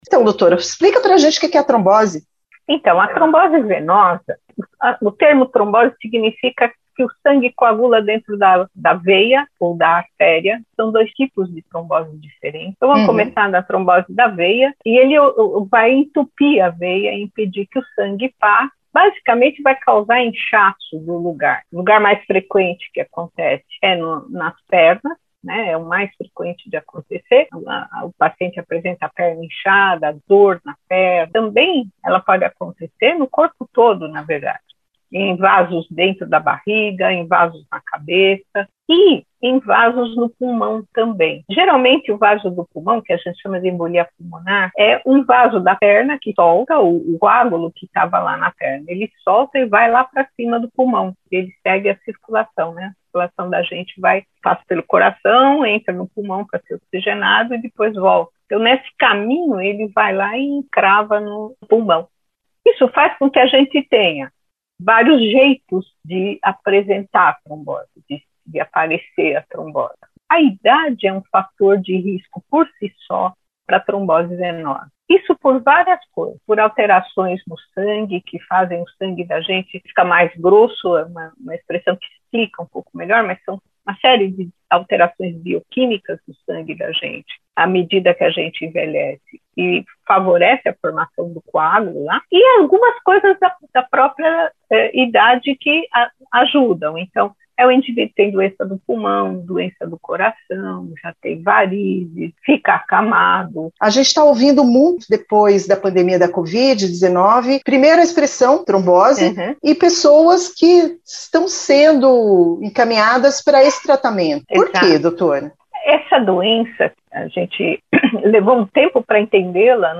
Hematologista explica o que é a trombose e os fatores de risco